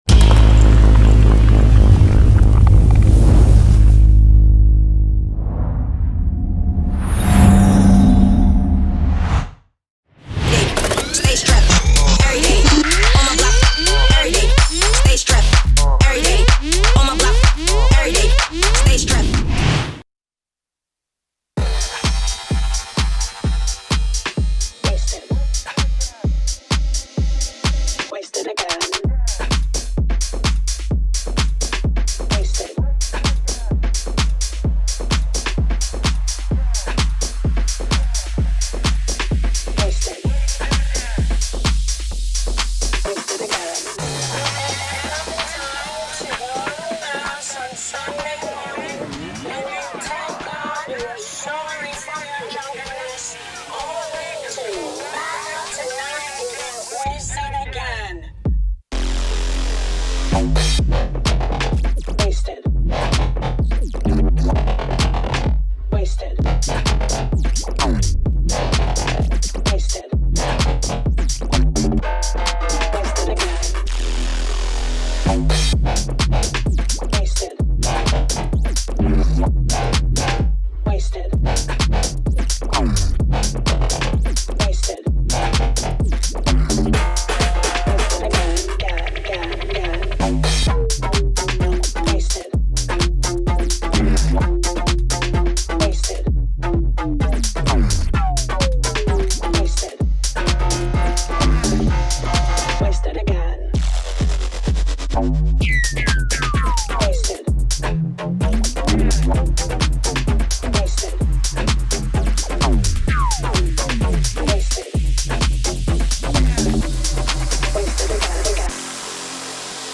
Also find other EDM